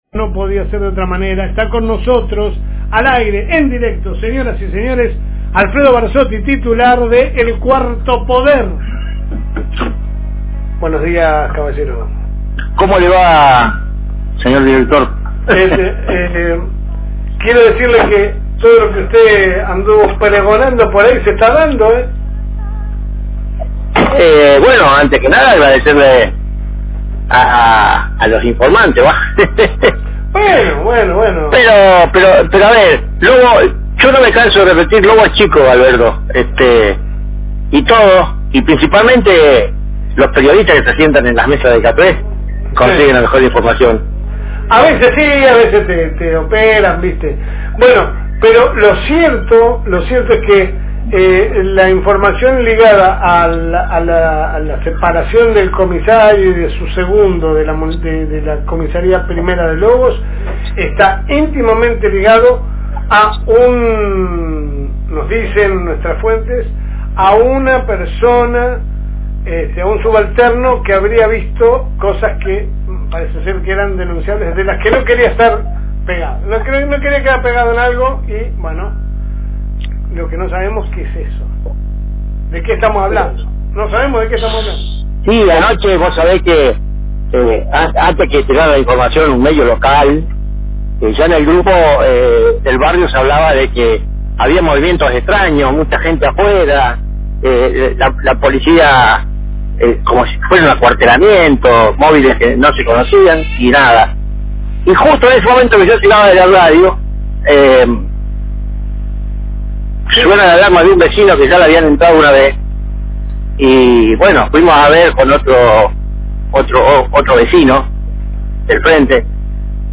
Charlas de Radio